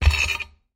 Звуки штанги